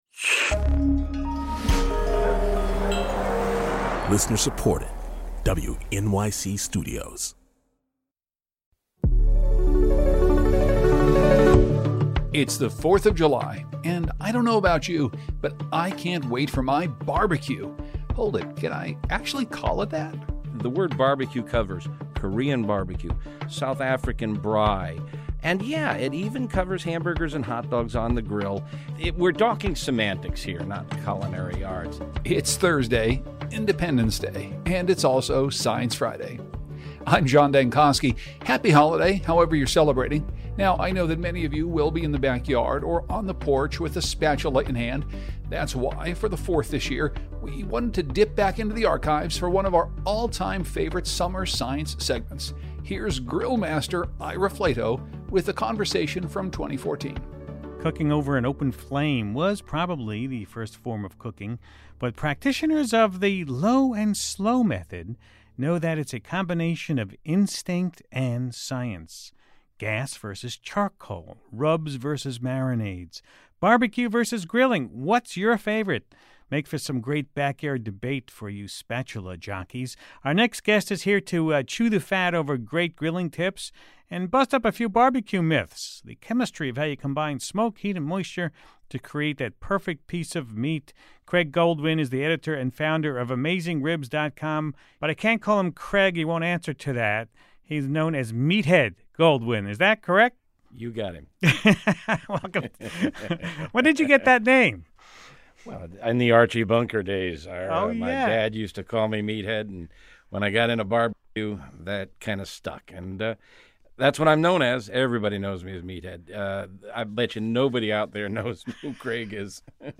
In a conversation from 2014, Ira talks marinade myths, charcoal chemistry, and the elusive “smoke ring”—the science behind barbecue and grilling.